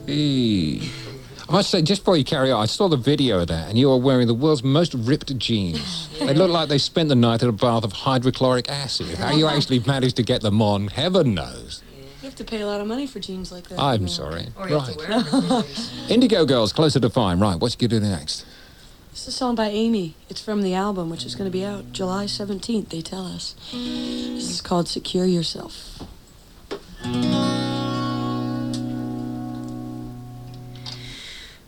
lifeblood: bootlegs: 1989-07-01: radio one - london, england
05. interview (0:31)